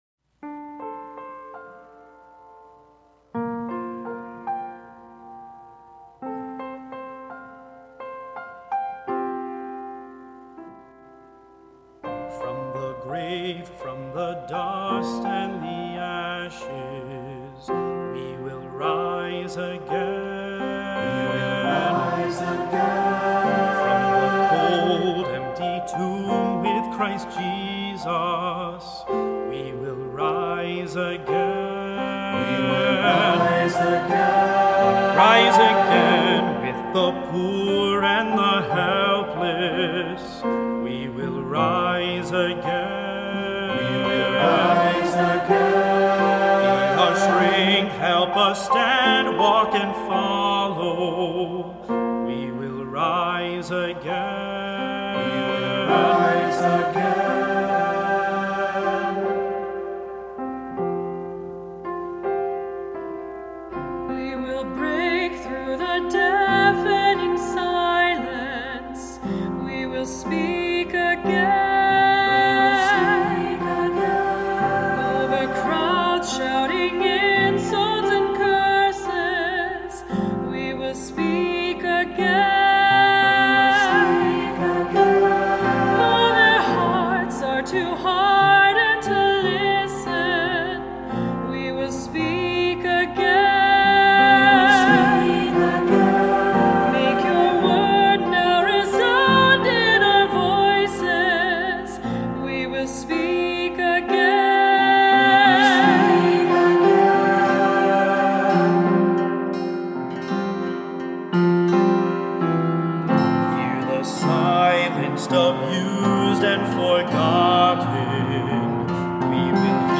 Voicing: SAB; Cantor; Assembly